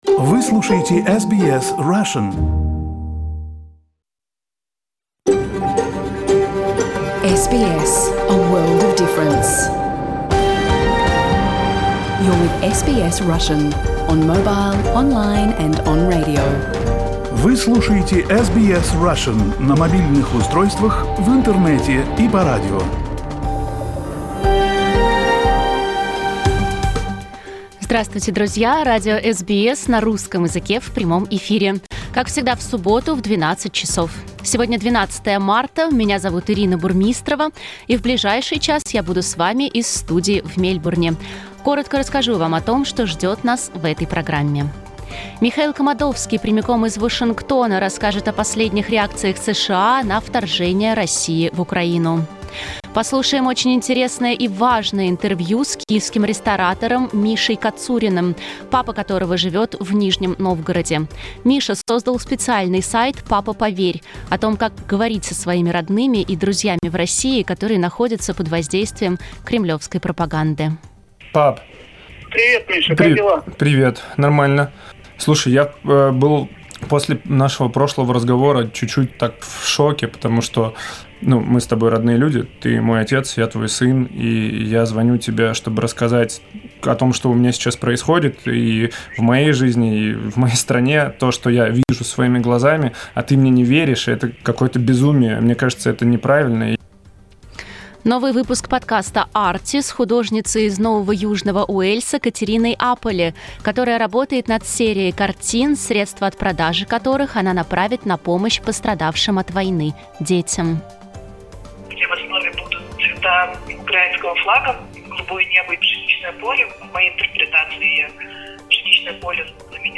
If you missed the broadcast, you can listen to it in its entirety without internal ad units in our podcasts. We also cut the news release before publication, as you can listen to it as a separate podcast.